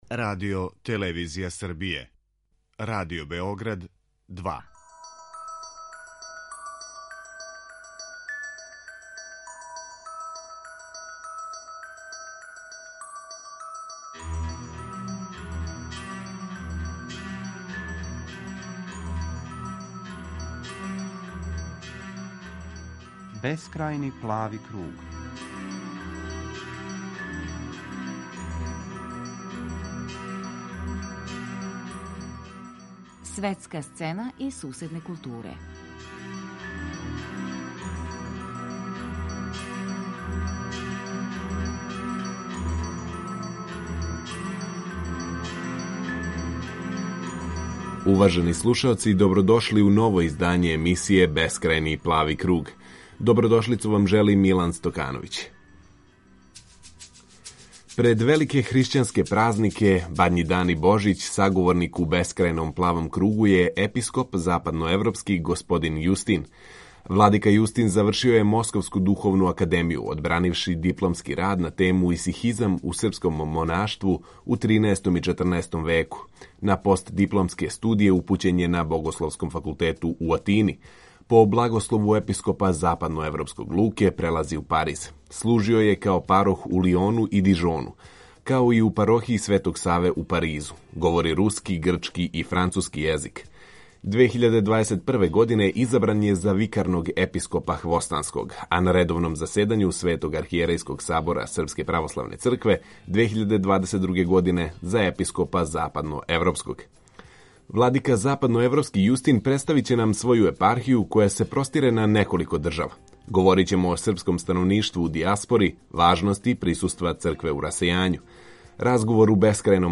Божић у Западној Европи - разговор са Епископом Јустином
Владика западноевропски Јустин, саговорник у Бескрајном плавом кругу